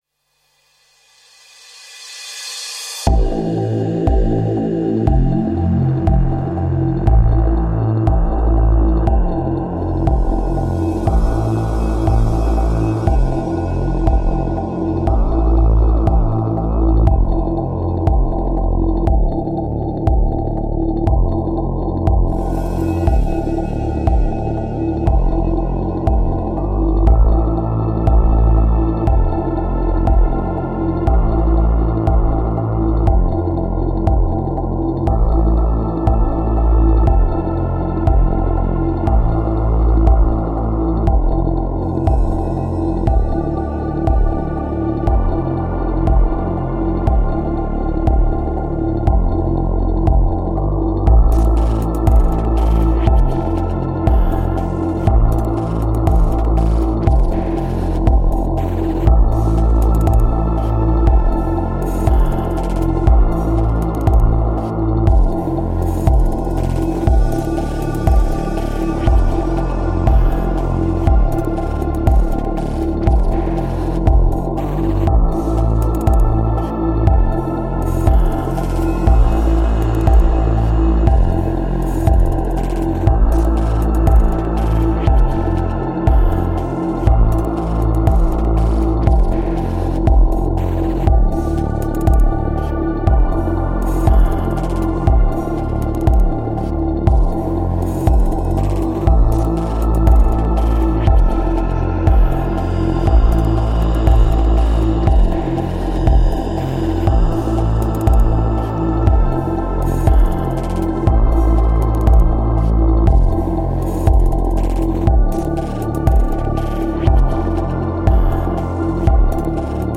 Мелодичный фон о зле и дьяволе